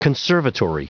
Prononciation du mot conservatory en anglais (fichier audio)
Prononciation du mot : conservatory